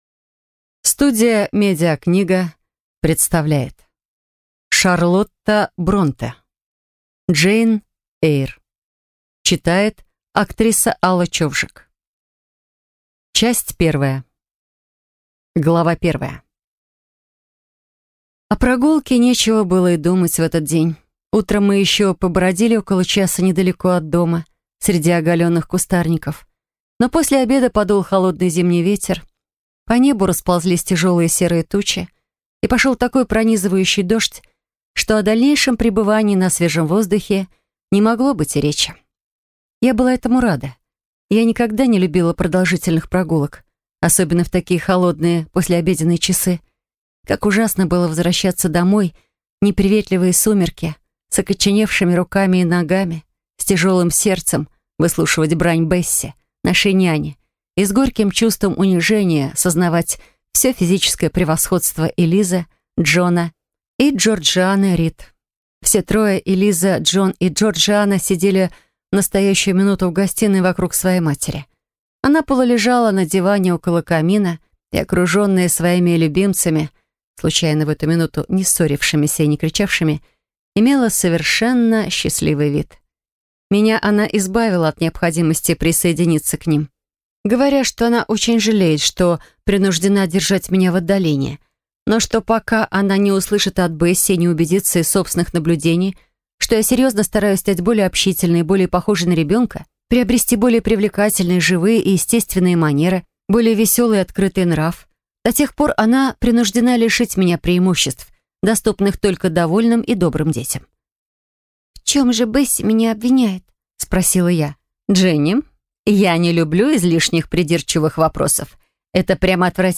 Аудиокнига Джейн Эйр | Библиотека аудиокниг